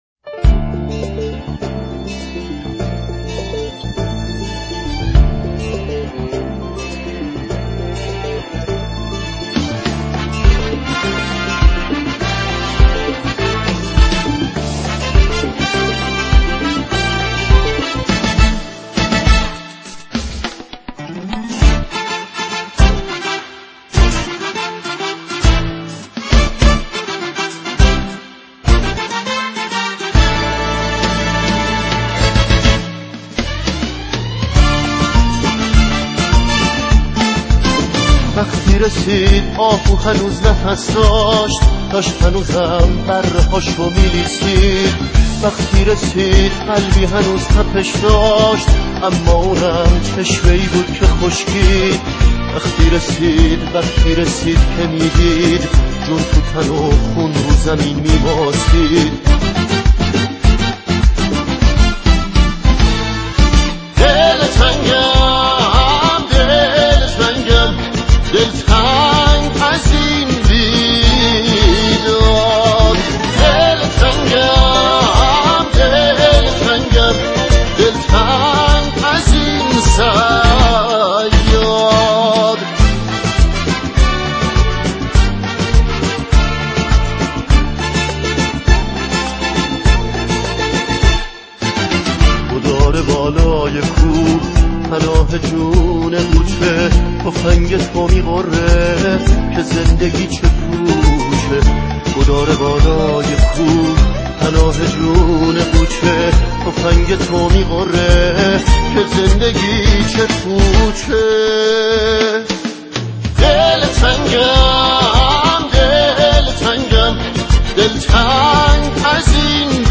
کارائوکی (آهنگ خالی مخصوص خوانندگی)